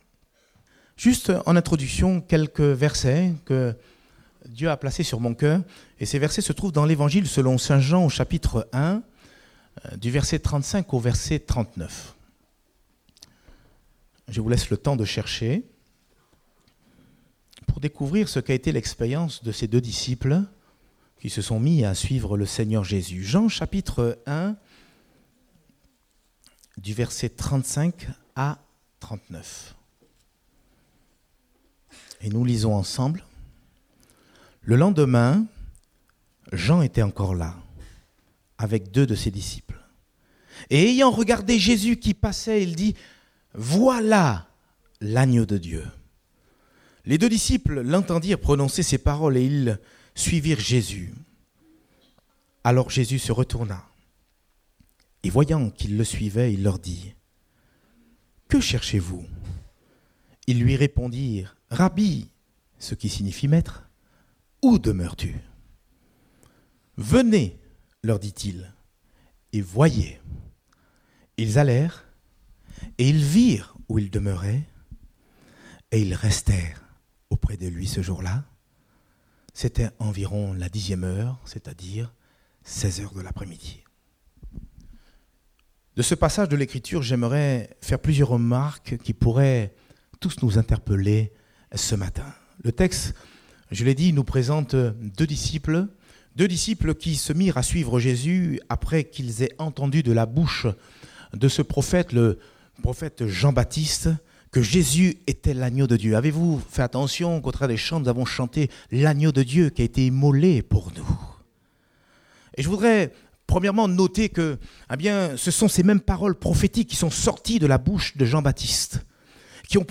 Date : 10 mars 2019 (Culte Dominical)